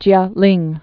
(jyälĭng)